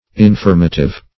Search Result for " infirmative" : The Collaborative International Dictionary of English v.0.48: Infirmative \In*firm"a*tive\, a. [Cf. F. infirmatif.]